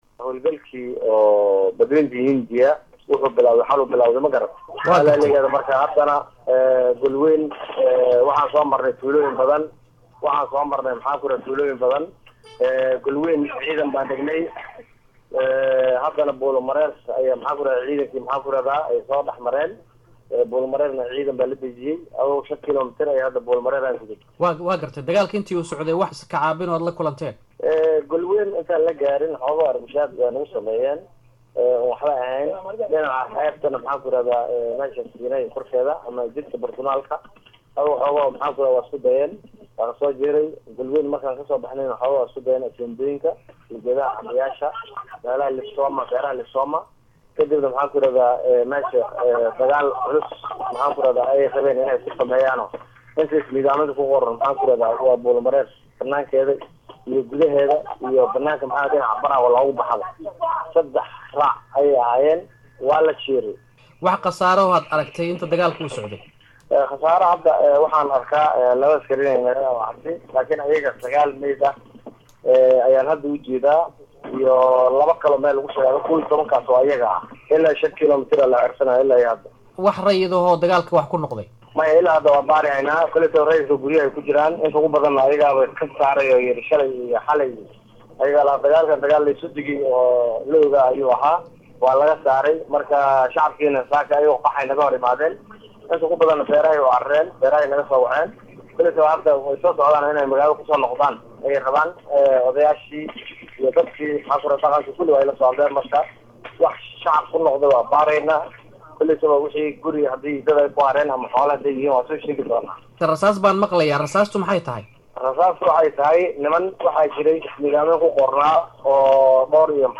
siidiiGudoomiyaha Gobolka Shabellaha Hoose C/qaadir Maxamed Nuur”Siidii” oo Wareeysi siiyay Idaacada VOA ayaa ka hadlay Dagaalkii ka dhacay Gobolka Shabellaha Hoose.